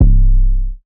archived music/fl studio/drumkits/slayerx drumkit/808s